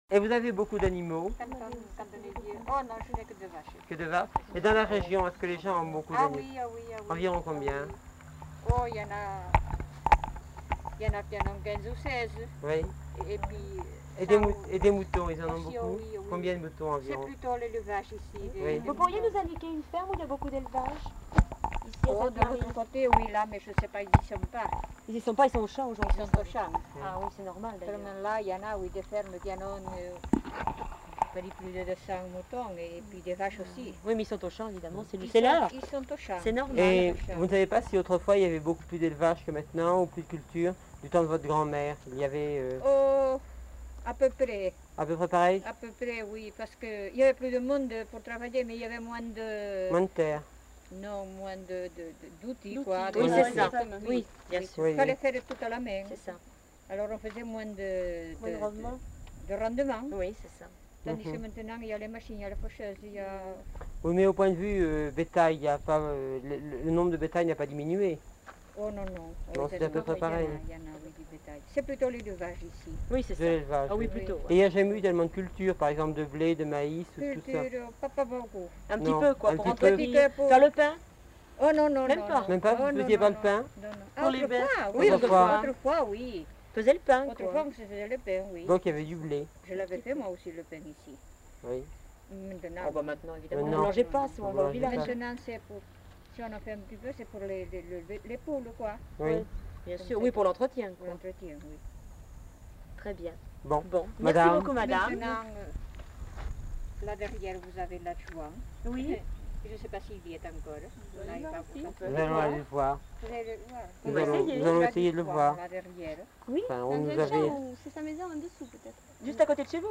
[Ministère de la jeunesse et des sports. Jeunesse et éducation populaire. Groupe de stagiaires. 1967] (enquêteur)
Genre : témoignage thématique
Notes consultables : L'informatrice n'est pas identifiée.